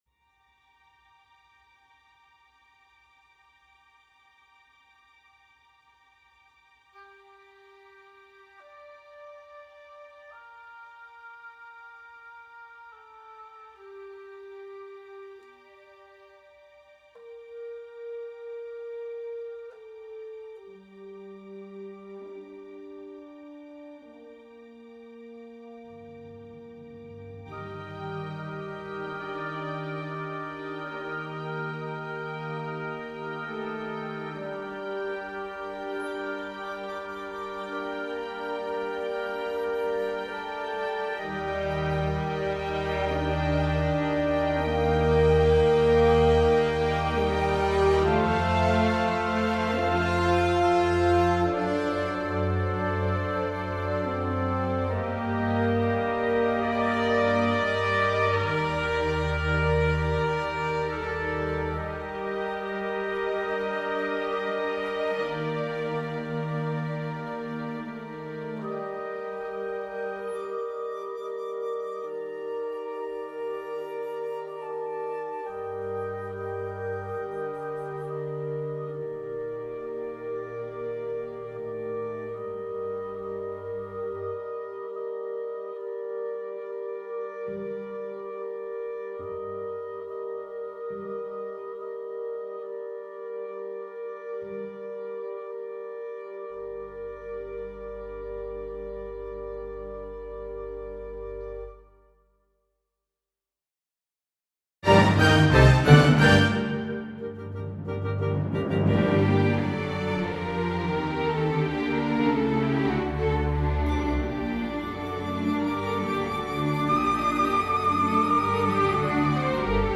As the title suggests, it’s an orchestral piece based on Bach’s Little Fugue in G minor (BWV 578).